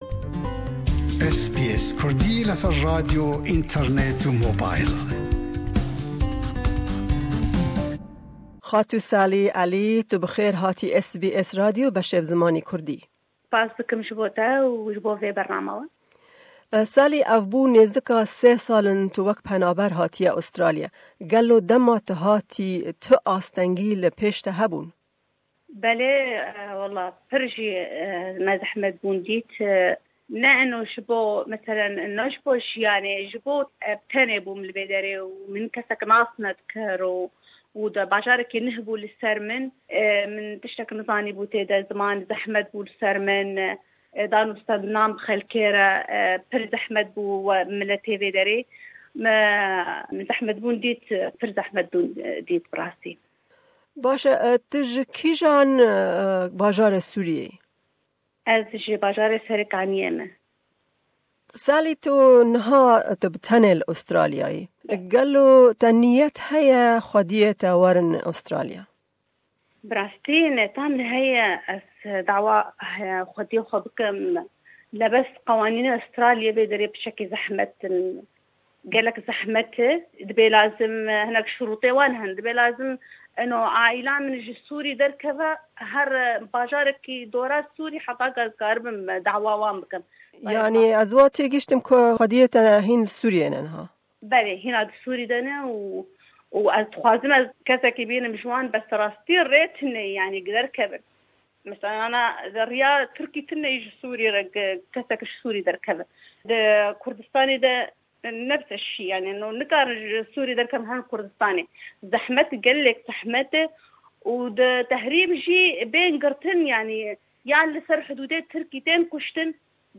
Ew di hevpeyvîne de herweha behsa dijwariyên peydakirina kar di rojnamevaniyê de li HK dike.